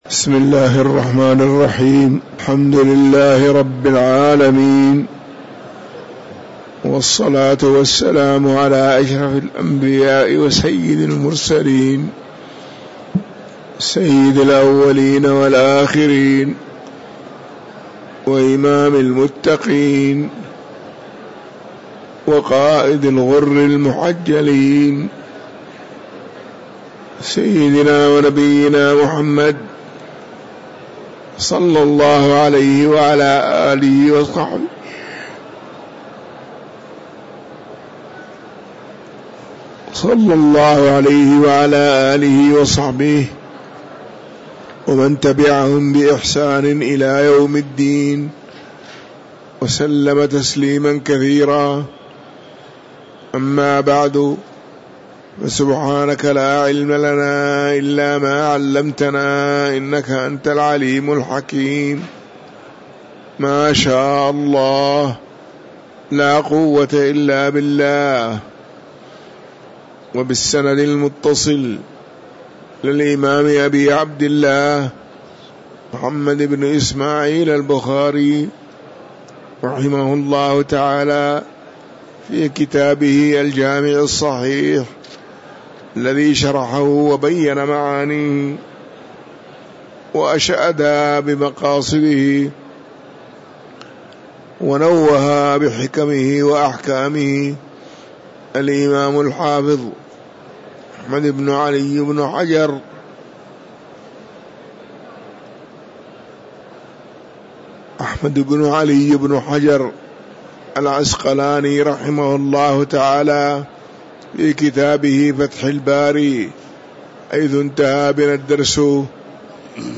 تاريخ النشر ١٥ شعبان ١٤٤٥ هـ المكان: المسجد النبوي الشيخ